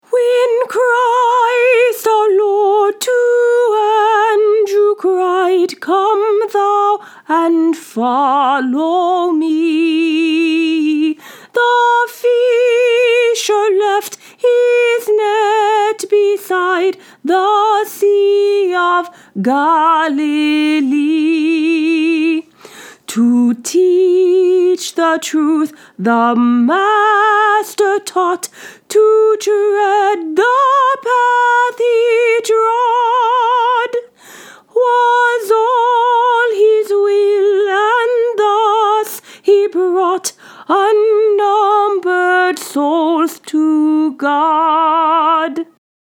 “ When Christ Our Lord To Andrew Cried”  Text: E.M. Barrett Tune: William Croft’s St. Anne , c. 1700 Sing along with me to learn verse one of this hymn.